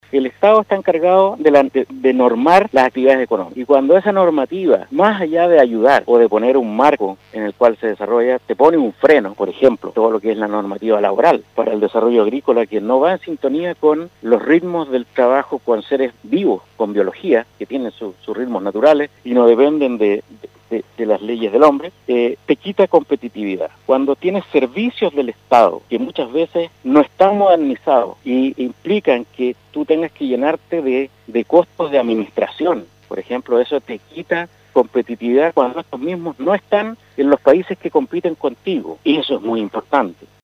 en conversación con el programa “Campo al Día” de Radio SAGO